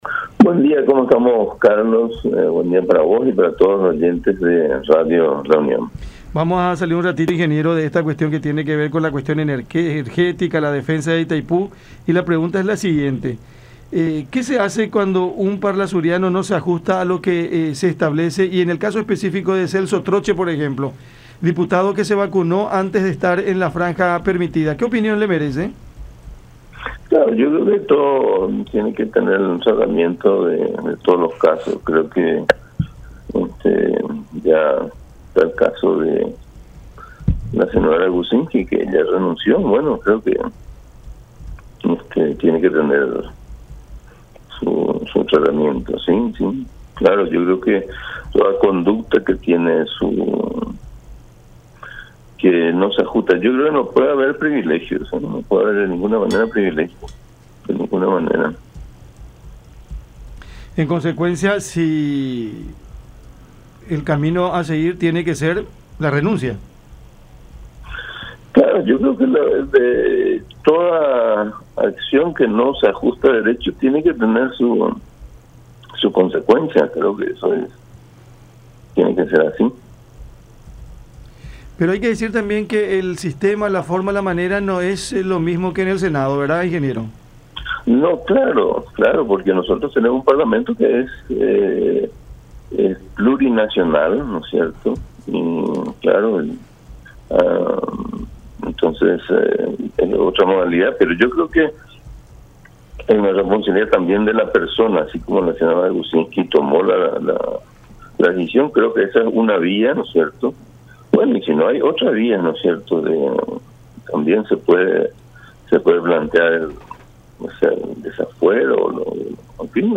“Toda conducta que no se ajusta a derecho tiene que tener su consecuencia. No puede haber privilegios, de ninguna manera”, manifestó Ricardo Canese, parlasuriano del Frente Guasu, en diálogo con La Unión, en alusión a su colega Troche, cuya aplicación de la primera dosis contra el coronavirus, registrada el 13 de abril en una Unidad de Salud Familiar de Caazapá (cuando en ese entonces recién estaba habilitada la inmunización a adultos mayores de 85 años), fue descubierta tras la publicación de una lista de “vacunados VIP”.